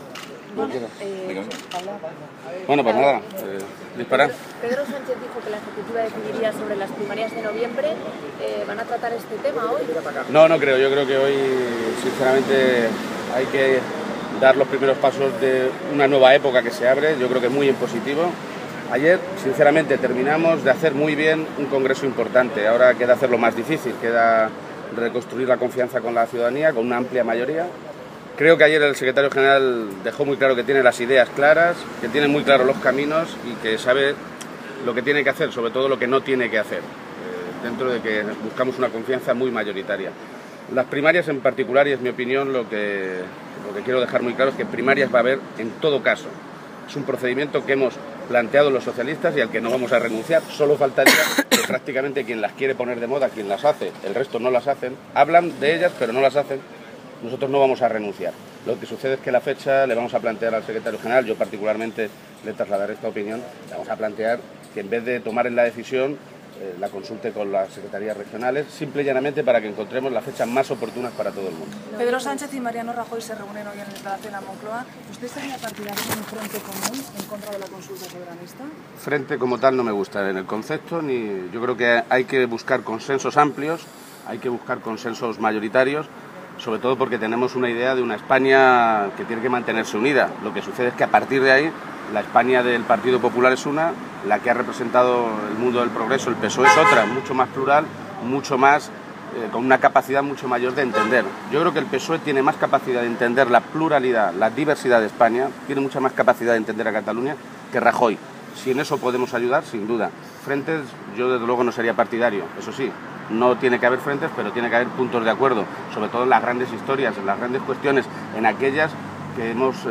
Page sostenía, a preguntas de los medios de comunicación, que «ayer, en su discurso, el nuevo secretario general demostró que tiene muy claras las cosas que tiene que hacer y también las que no tiene que hacer», y expresó su convicción de que el trabajo inminente tiene que tener como objetivo apuntalar «los primeros pasos de una nueva época que se abre hoy en el partido».
Cortes de audio de la rueda de prensa